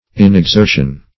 Search Result for " inexertion" : The Collaborative International Dictionary of English v.0.48: Inexertion \In`ex*er"tion\, n. Lack of exertion; lack of effort; defect of action; indolence; laziness.